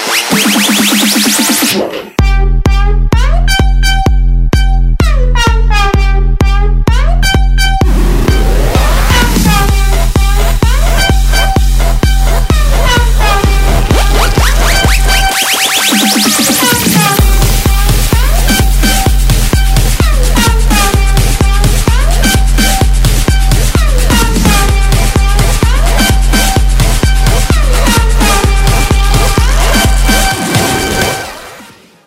Из клубной музыки [37]